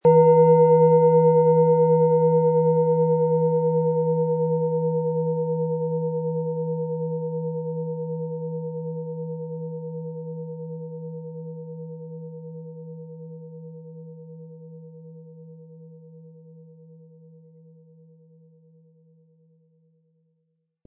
Planetenschale® Freudig sein & In den Bauch spüren mit Hopi-Herzton & Mond, Ø 18,2 cm, 500-600 Gramm inkl. Klöppel
• Mittlerer Ton: Mond
PlanetentöneHopi Herzton & Mond
MaterialBronze